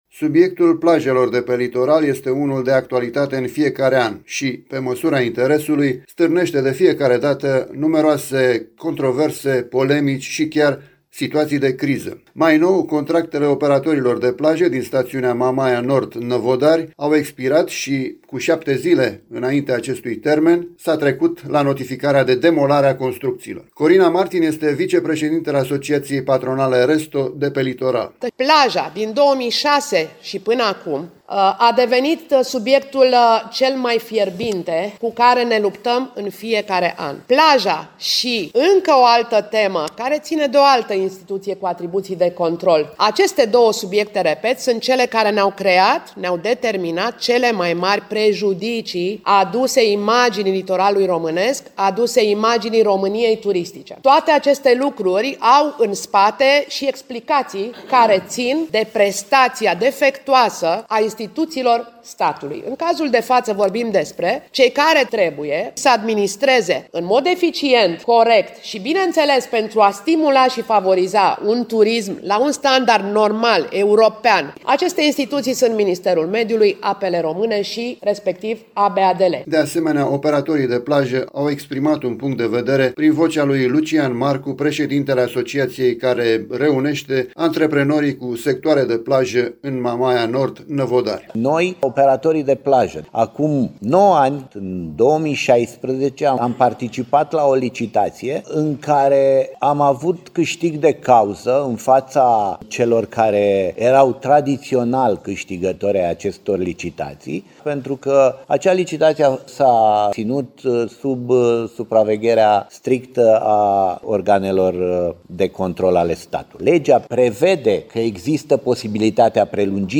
Detalii de la conferința de presă organizată ieri de reprezentanții operatorilor de plajă din Patronatele Năvodari, Eforie și RESTO Constanța